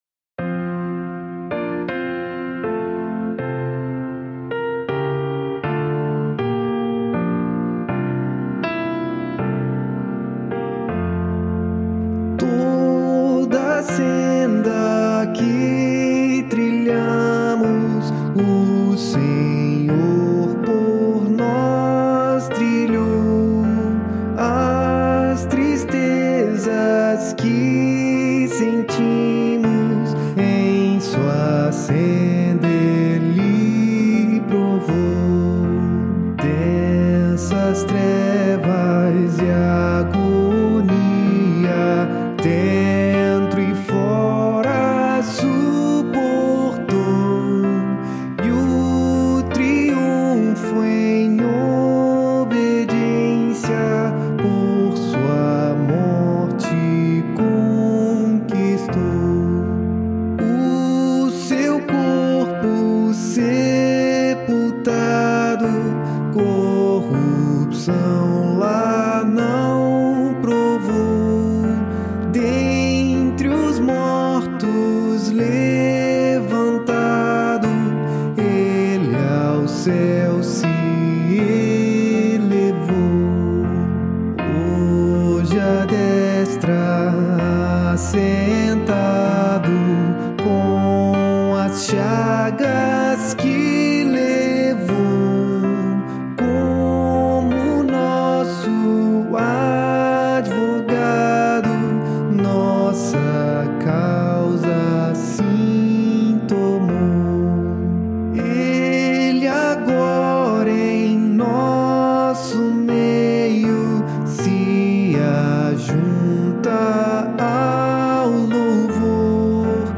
8.7.8.7.D
Adoração e Louvor